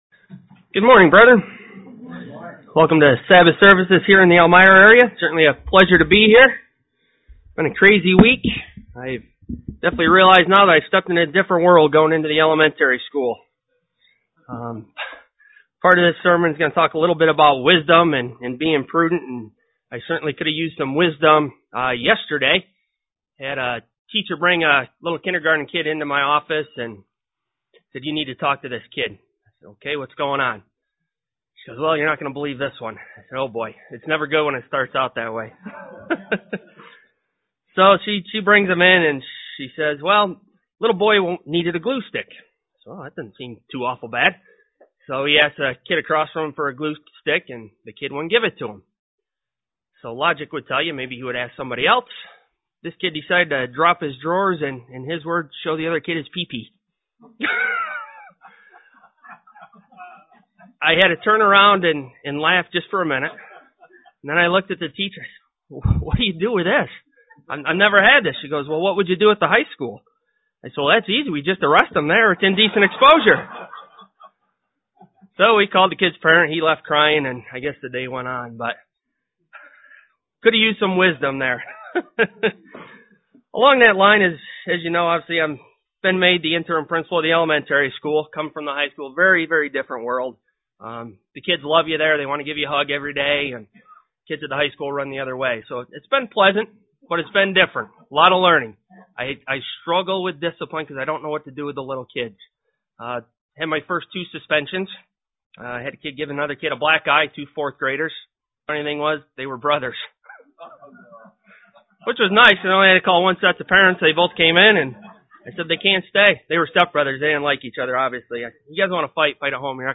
Print God's view of knowledge and how to understand what is knowledge UCG Sermon Studying the bible?
Given in Elmira, NY